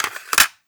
LOAD_CASSETTE_08.wav